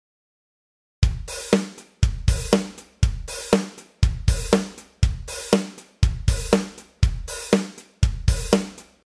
ハイハット：8分で8回刻む（曲調によって4分x4にしたり、オープン・クローズを織り交ぜたりも）
スネア：3・7で叩く
バスドラ：1・5・6で踏む
↓の音源は、ハイハットの2・6だけオープンにした例です。